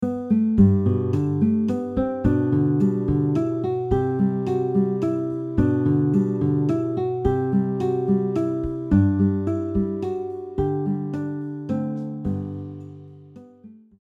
• Guitar arrangement